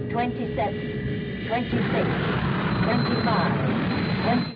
The quality is kept to a minimum because of webspace limitations.